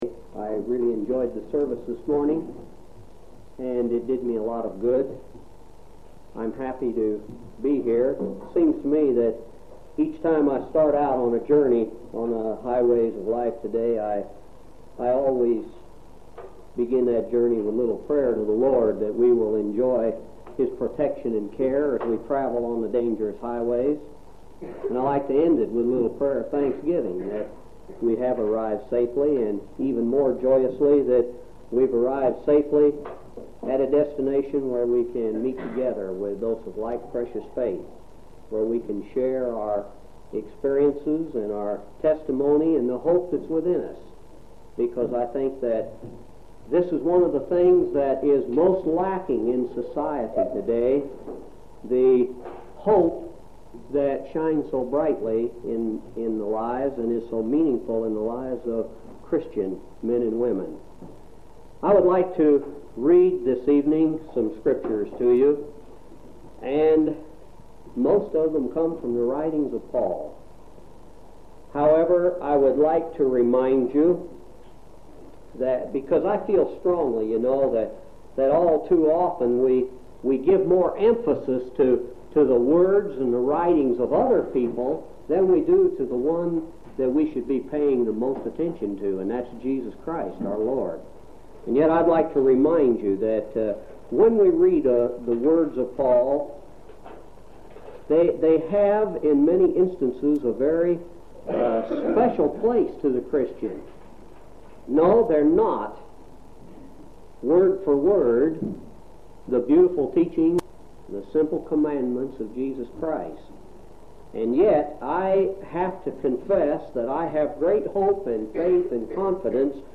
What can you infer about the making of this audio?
6/11/1975 Location: Grand Junction Local Event